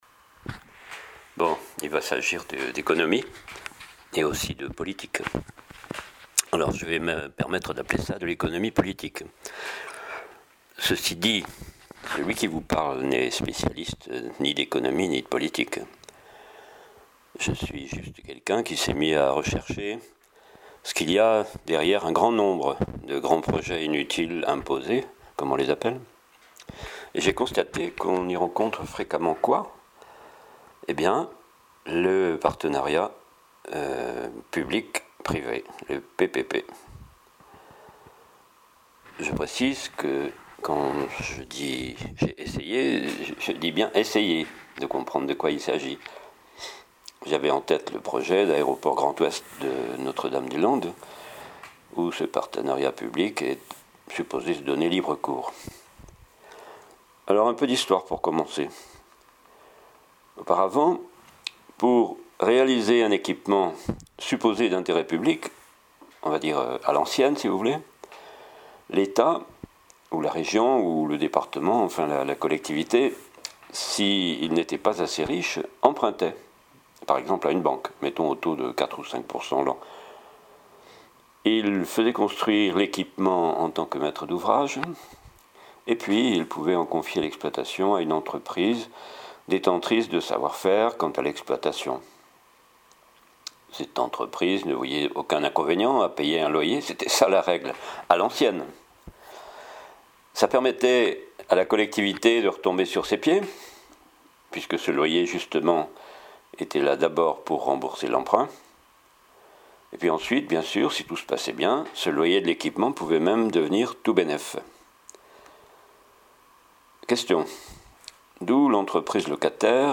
21 Décembre sur le Partenariat Public Privé : Ecouter: (22mn) Au début ça cahote un peu (micro mal fixé) mais ensuite ça s'écoute correctement ! Ce PPP fait les beaux jours d'un capitalisme sans cesse modernisé...
Exposé oral de 20' sur le PPP.MP3